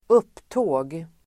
Uttal: [²'up:tå:g]